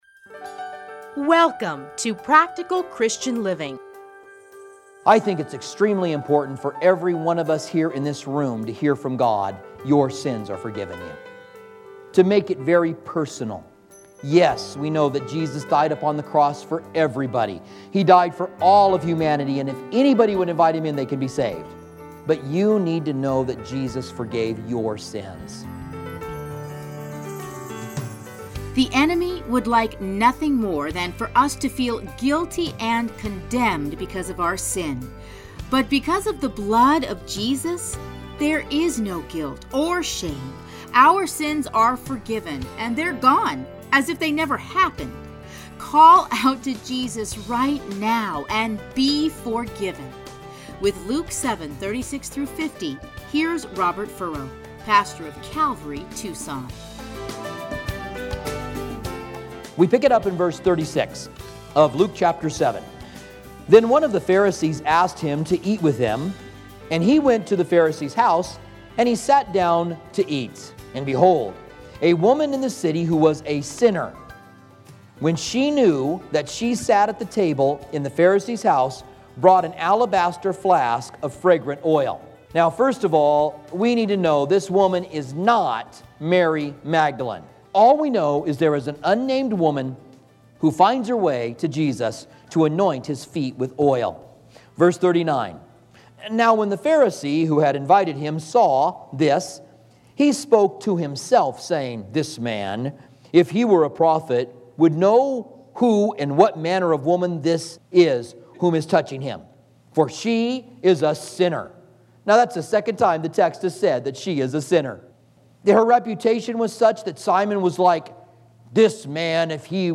teachings are edited into 30-minute radio programs